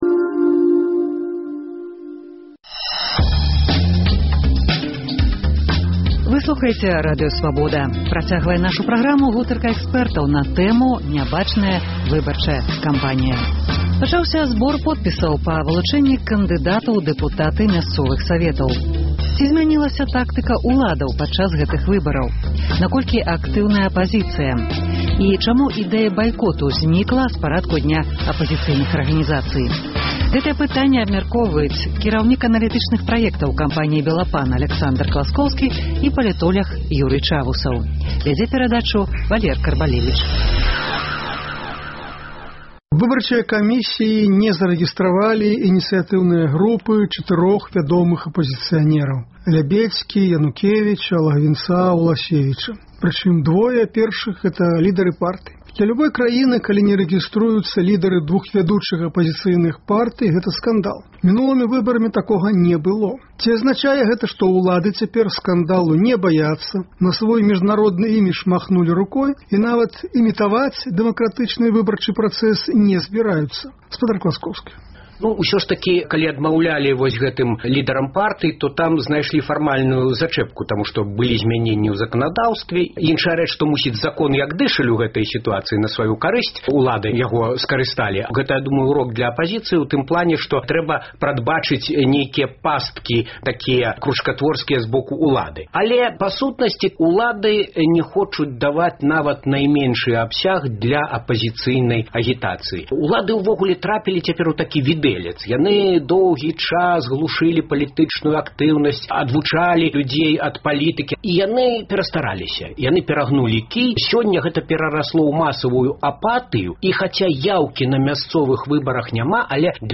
Чаму ідэя байкоту зьнікла з парадку дня апазыцыйных арганізацый? Гэтыя пытаньні абмяркоўваюць за круглым сталом